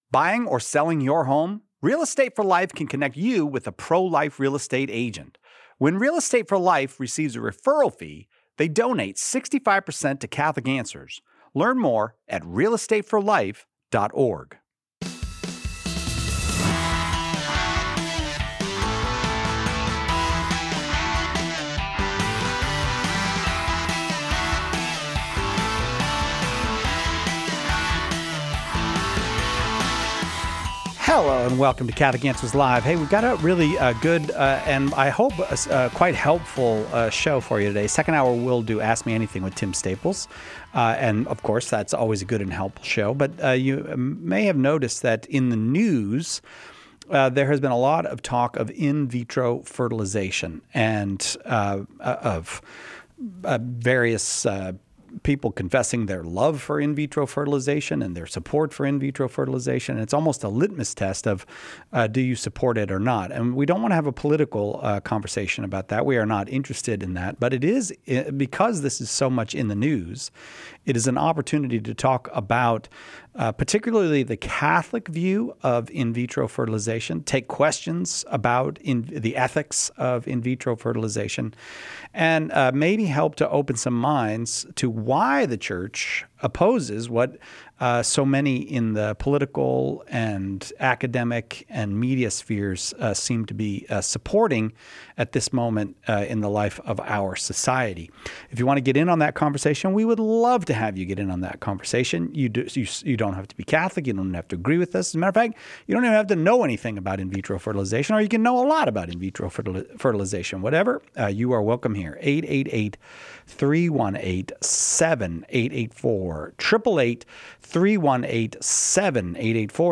Plus, discover resources to help infertile couples understand the Church’s teachings. Tune in for a thought-provoking discussion!